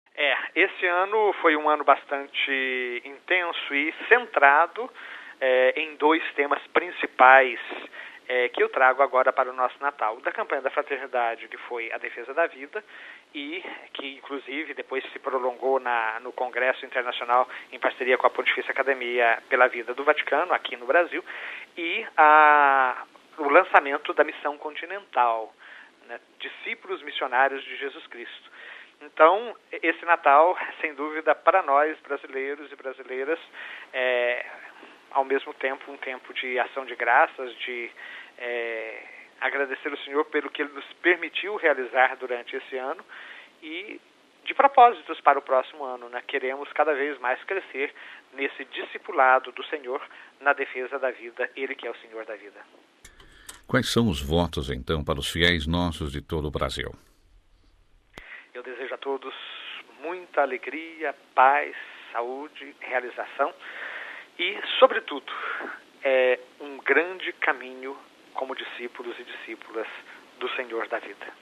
De Brasília ao Rio de Janeiro, para os votos do secretário-geral da CNBB, Dom Dimas Lara Barbosa, e bispo auxiliar do Rio de Janeiro: RealAudio